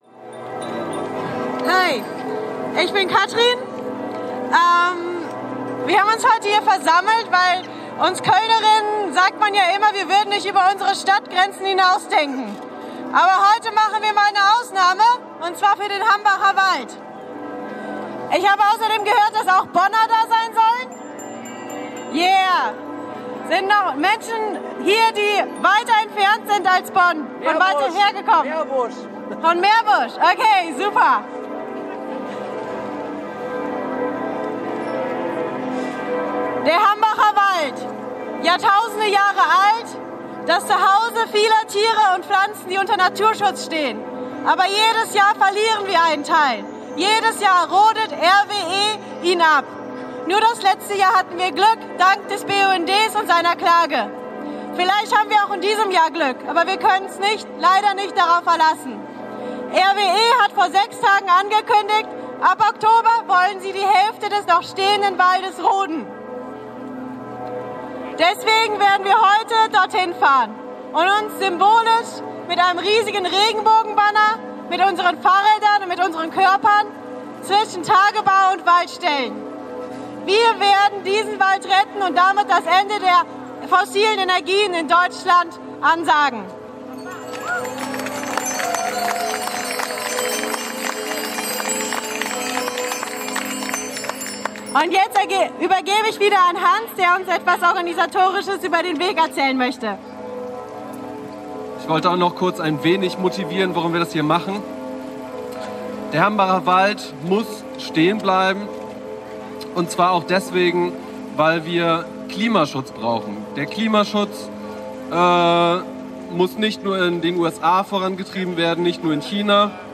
300 Braunkohleskeptiker demonstrierten vor dem Kölner Dom ...
Einführungsansprache